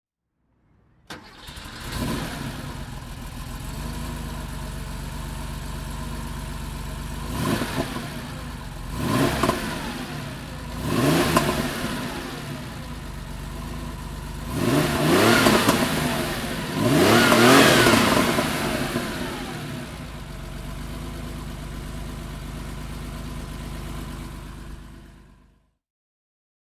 Motorsounds und Tonaufnahmen zu Porsche Fahrzeugen (zufällige Auswahl)